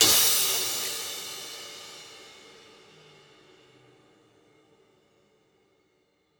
Clap - [TM88].wav